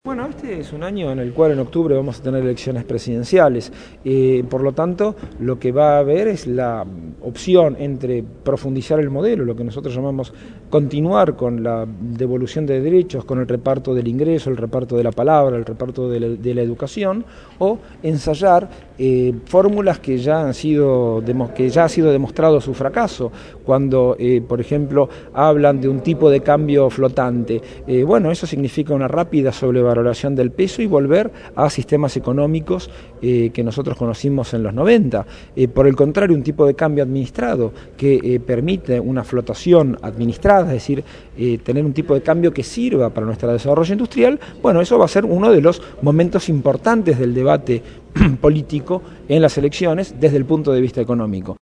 Producción y entrevista